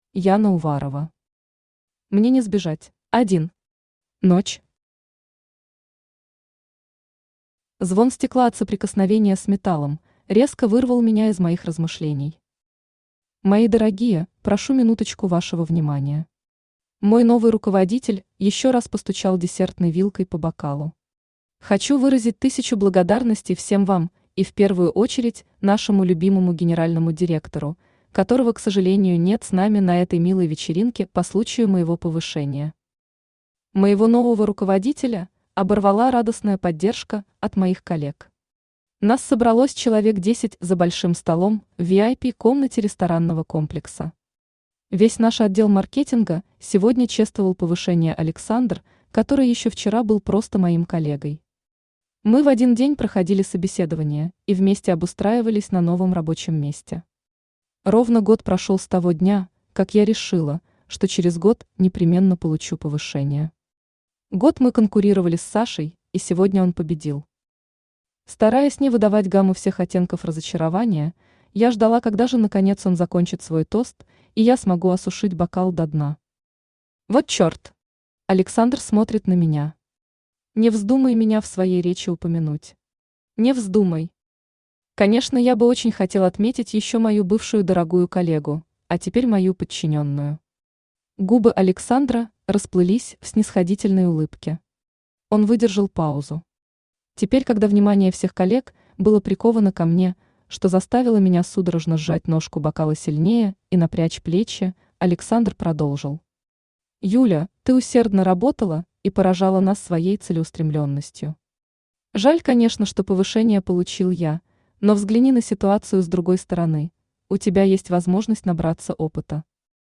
Аудиокнига Мне не сбежать | Библиотека аудиокниг
Aудиокнига Мне не сбежать Автор Яна Уварова Читает аудиокнигу Авточтец ЛитРес.